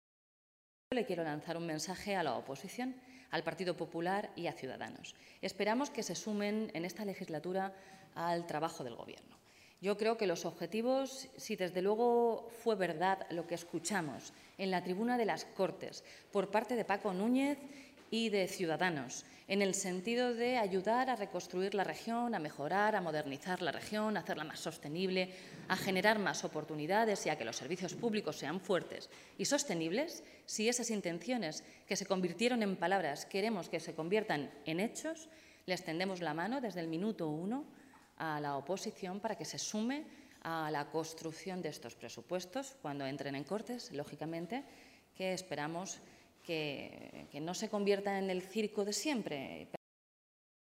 En la pasada legislatura el PP registraba cientos de enmiendas, muchas de ellas no eran más que papel mojado y otras muchas de ellas suponían recortes”. portavoz.gobierno_mano.tendida.oposicion_100719.mp3 Descargar: Descargar